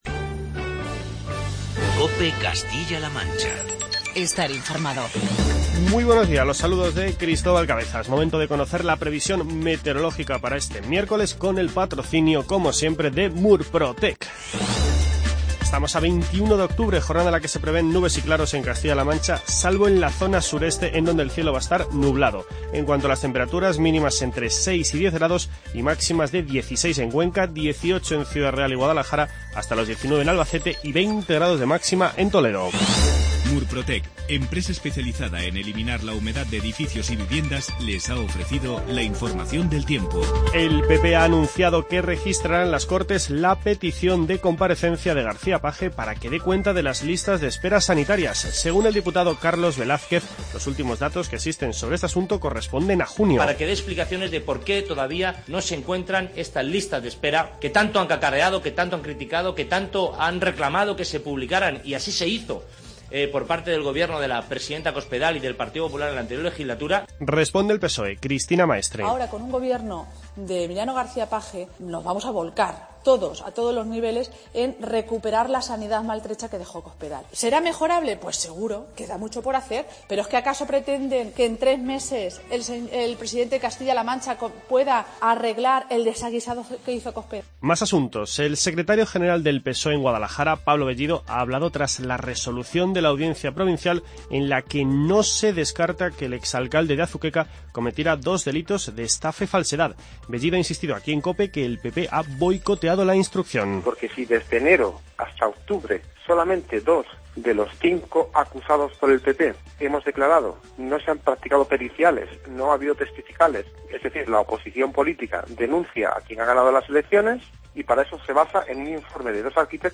Informativo regional y provincial